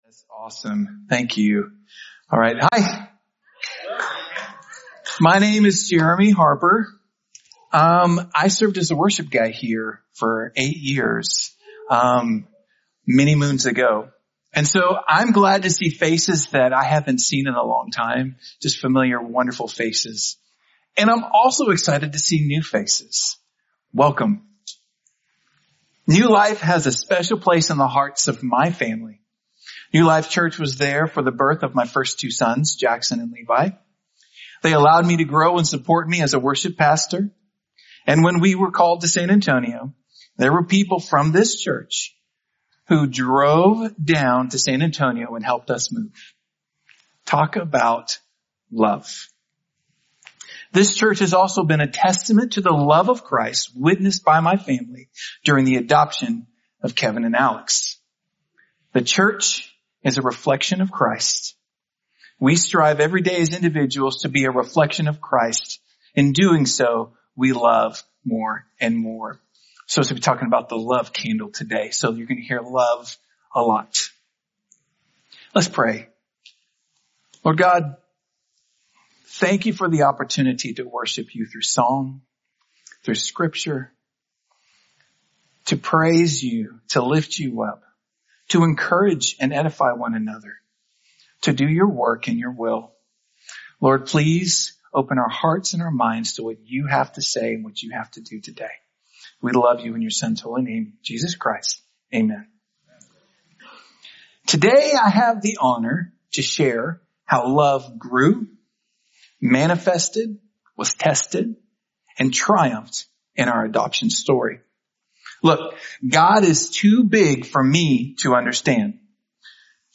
The Search Sermon